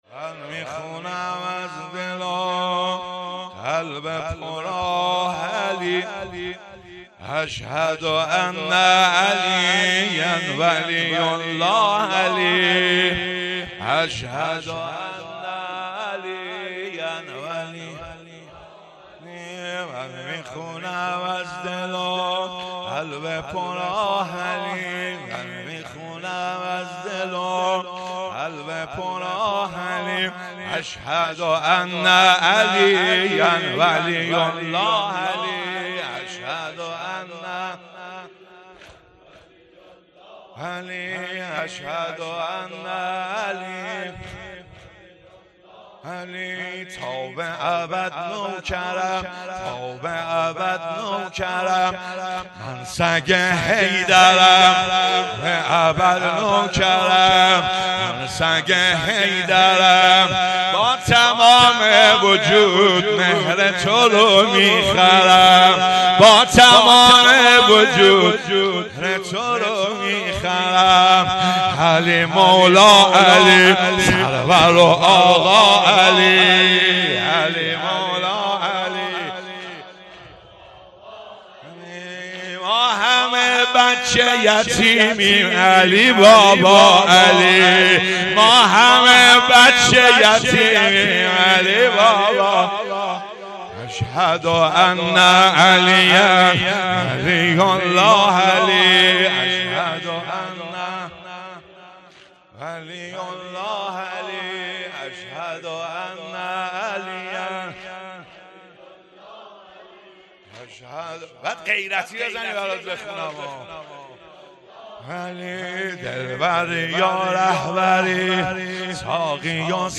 شهادت حضرت زهرا سلام الله علیها فاطمیه اول ۱۴۰۲